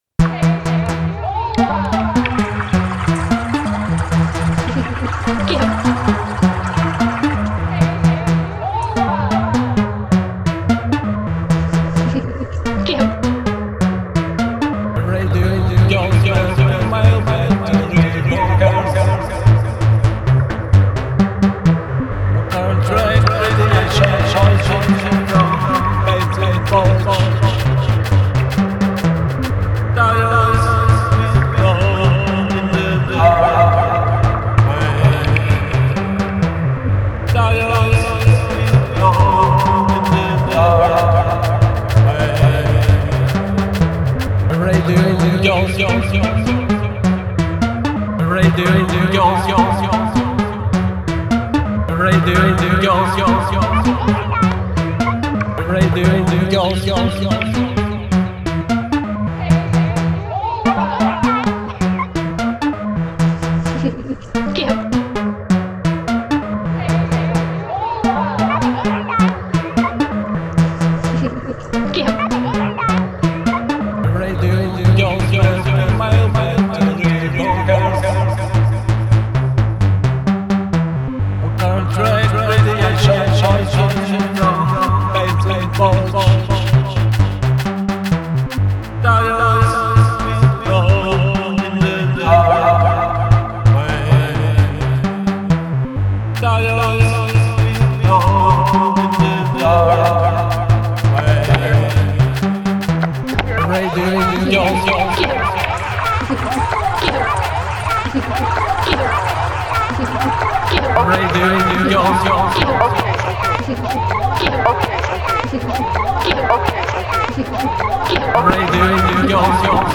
Genre: IDM, Minimal Wave, Synth-pop.